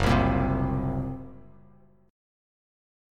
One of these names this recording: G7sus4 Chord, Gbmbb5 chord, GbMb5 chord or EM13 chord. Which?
GbMb5 chord